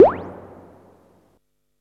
SIMMONS SDS7 14.wav